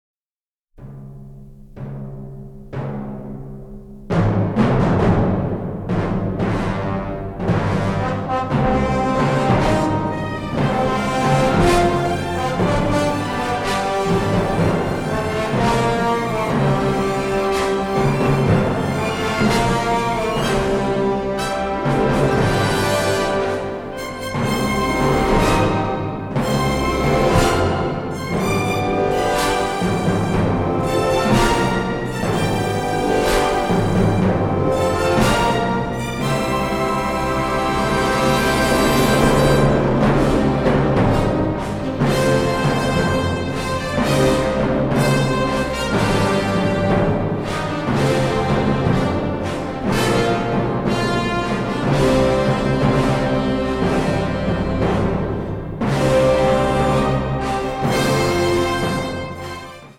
with stirring brass and powerful percussion figures.